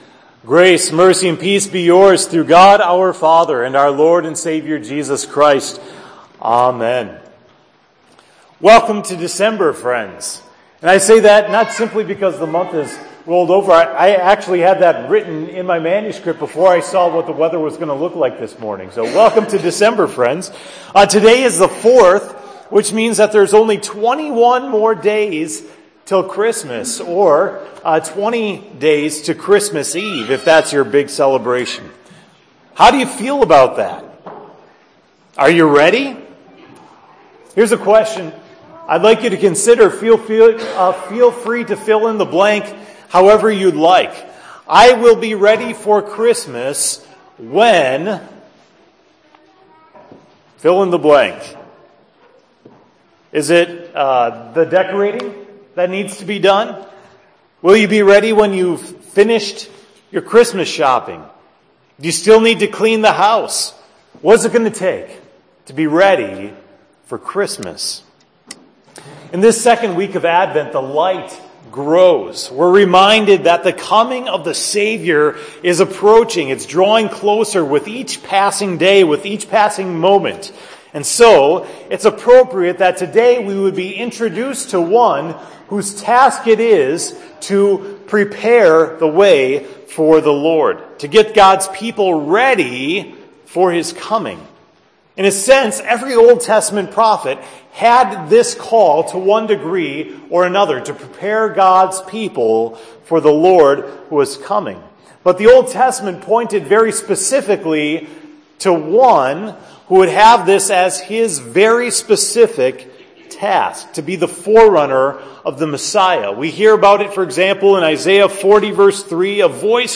The sermon for Sunday, December 4, 2016 (Advent II) at Hope Text: Luke 1:5-17